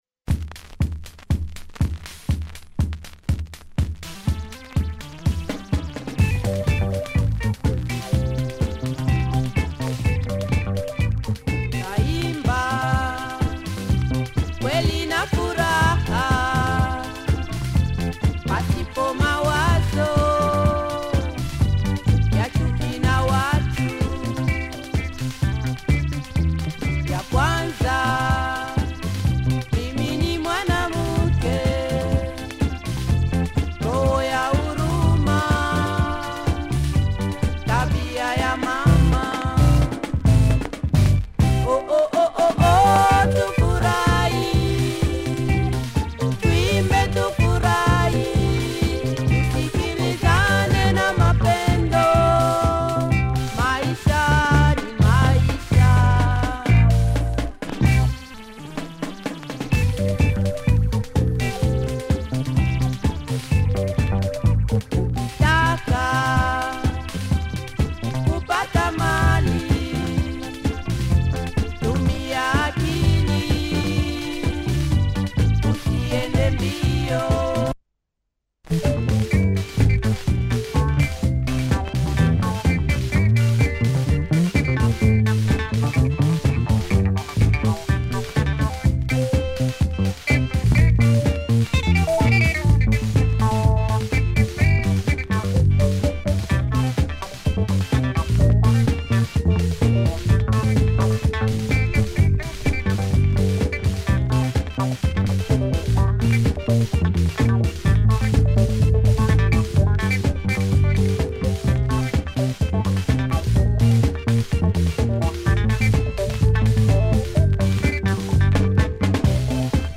Nice discoish track